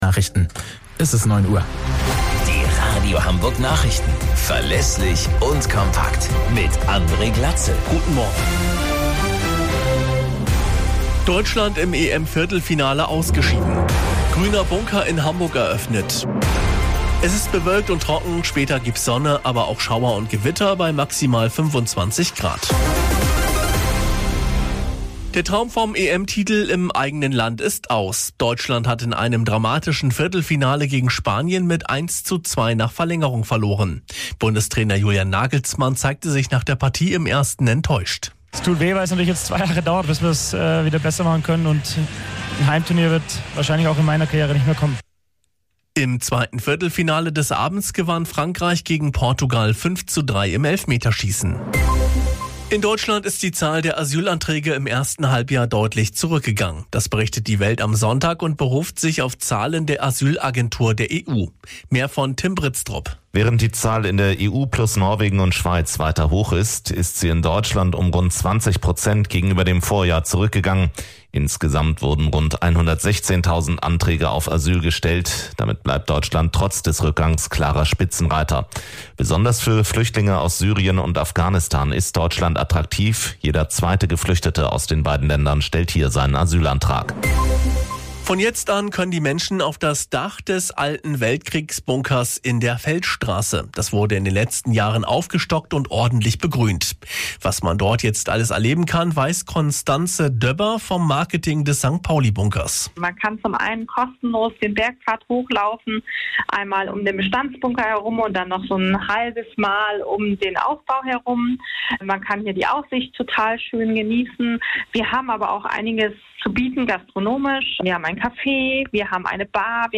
Radio Hamburg Nachrichten vom 06.08.2024 um 19 Uhr - 06.08.2024